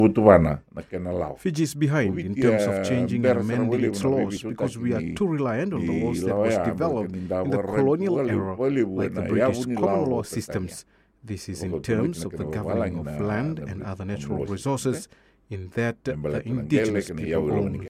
Speaking on Radio Fiji One’s “Na Noda Paraiminisita” program, he cited the presence of the iTaukei Trust Fund and Fijian Holdings Limited and stressed their crucial role in these deliberations.